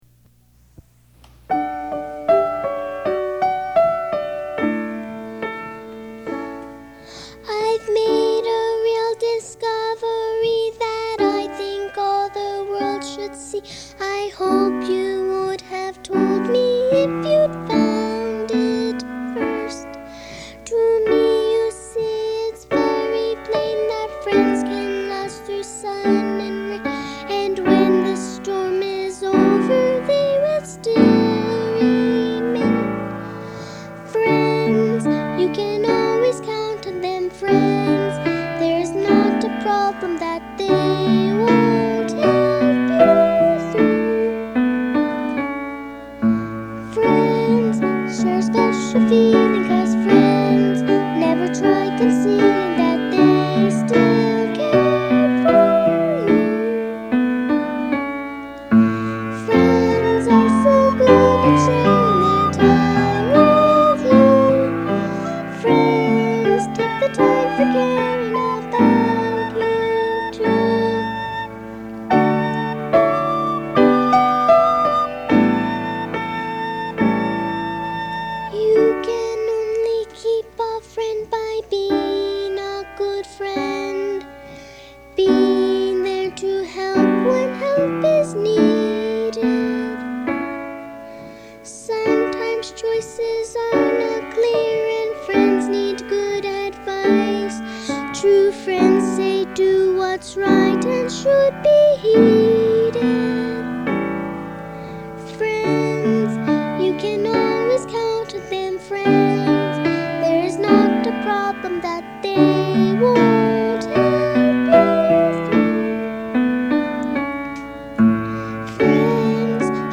unisson (1 voices children)
Children's song.
Tonality: D major